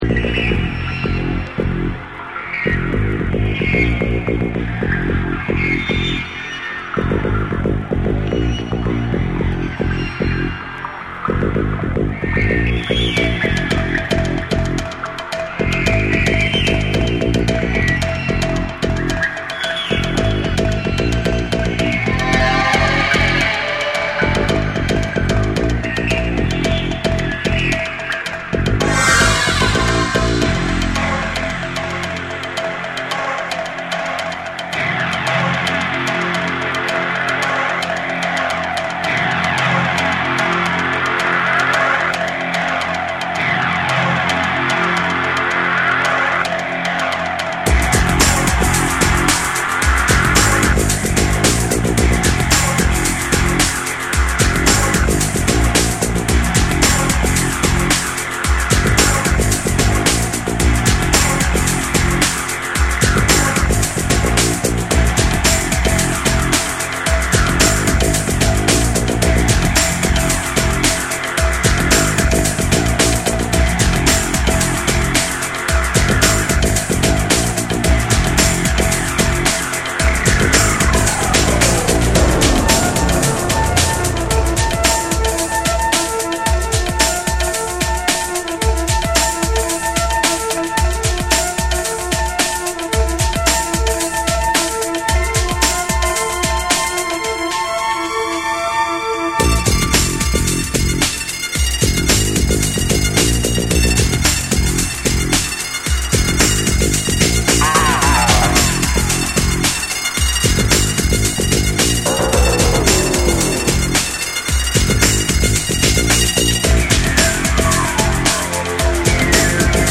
ノルウェーのエレクトロ・デュオ
BREAKBEATS